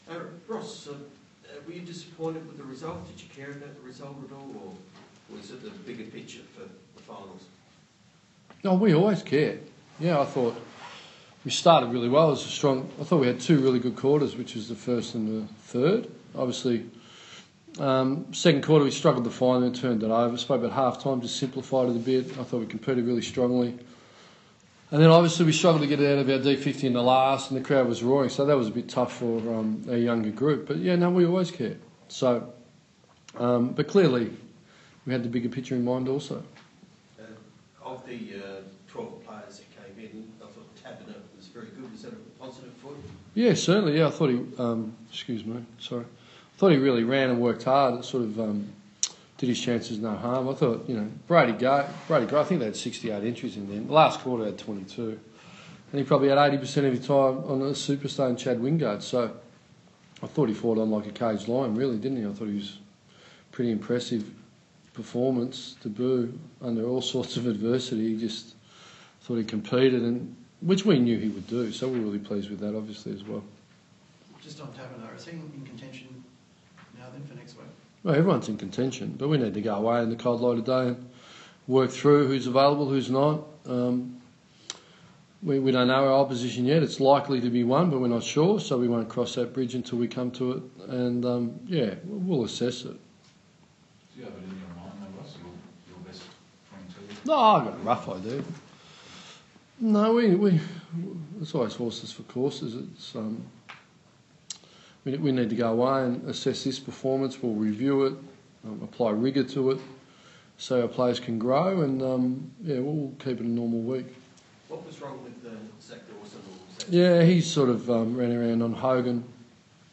Fremantle Dockers Coach Ross Lyon speaks after their 69-point loss to Port Adelaide